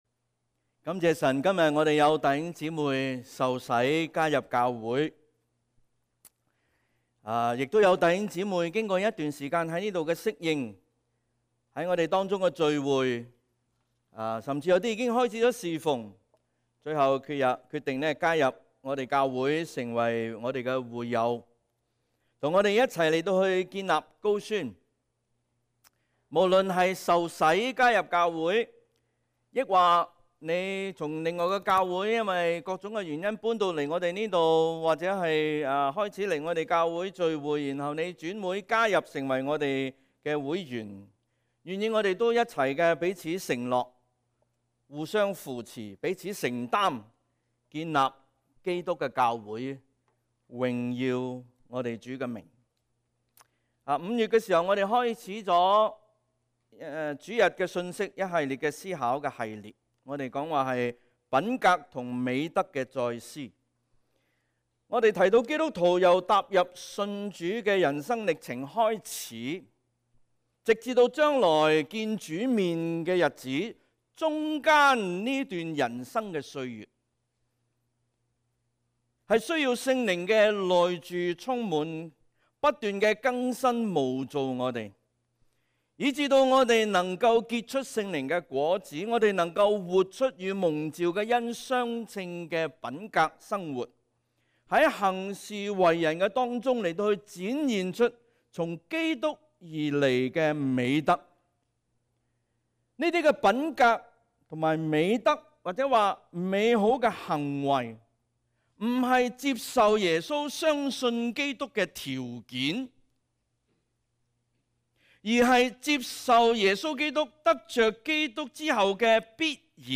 SERMONS | 講道 | Westwood Alliance Church